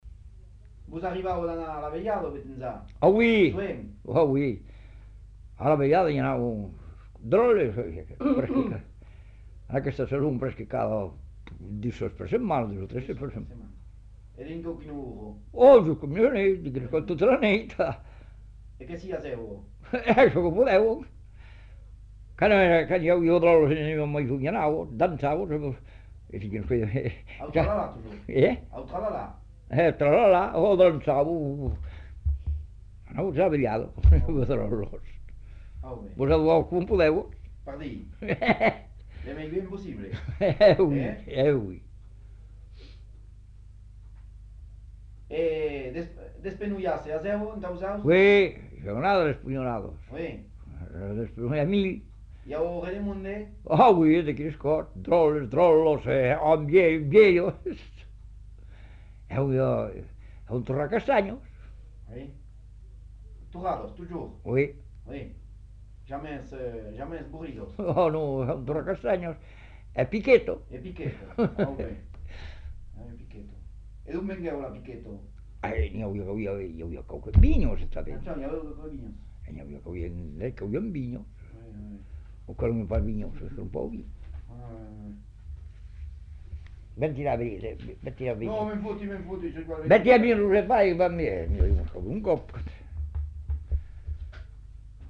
Aire culturelle : Savès
Département : Gers
Genre : témoignage thématique